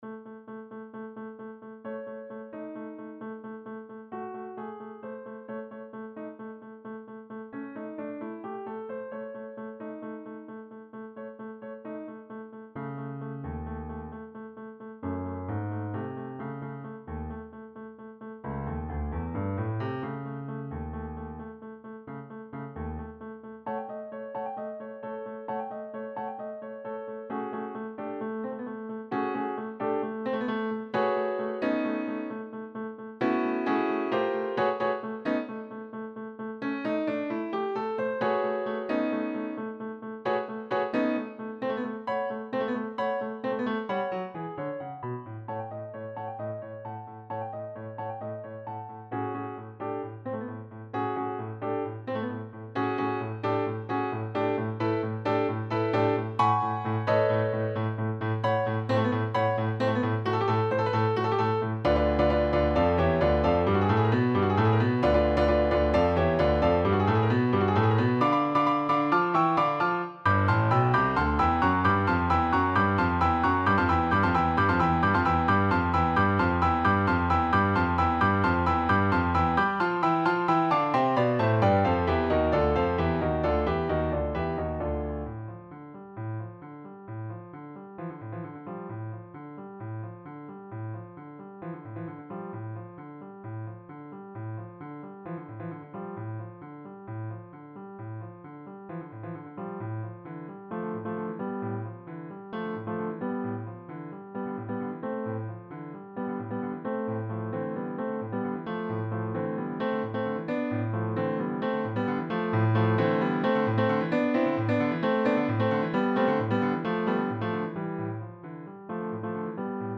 Here you have a version for piano solo.